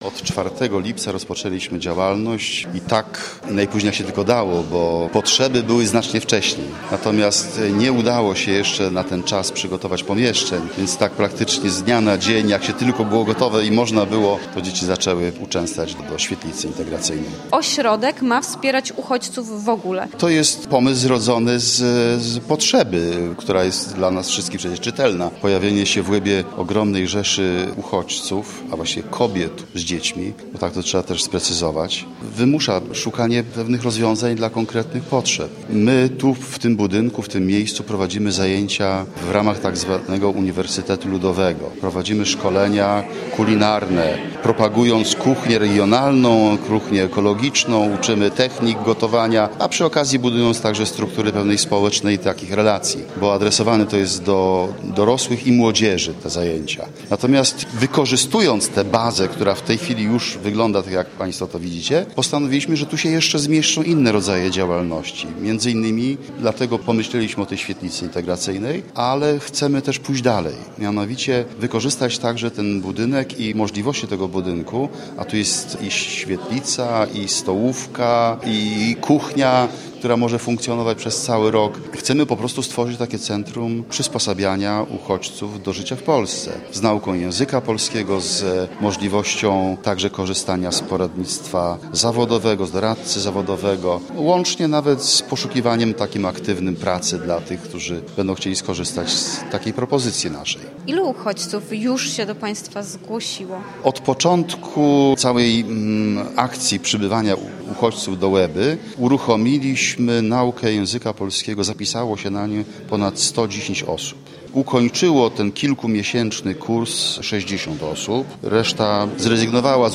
jedna z opiekunek dzieci oraz sami najmłodsi.